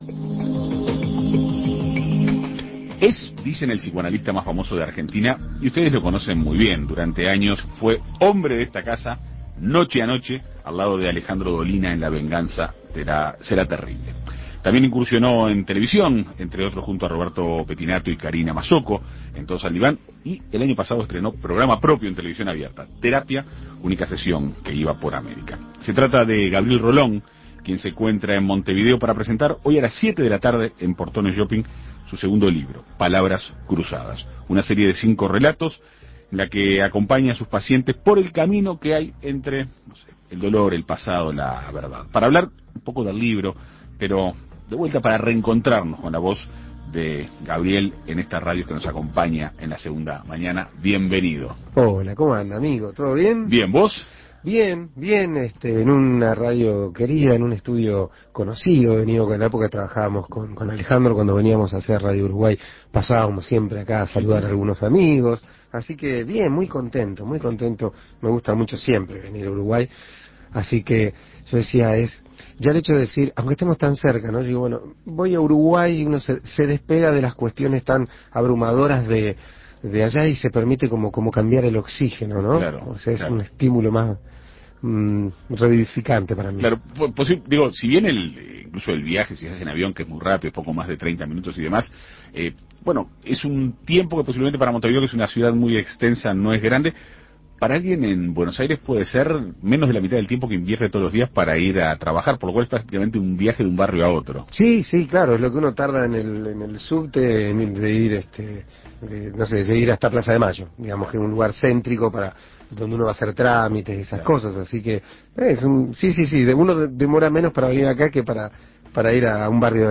Actualmente se encuentra en Uruguay presentando su segundo libro: "Palabras cruzadas", una serie de cinco relatos en los que acompaña a sus pacientes por el difícil camino que hay entre el dolor y la verdad. En Perspectiva Segunda Mañana dialogó con el psicoanalista.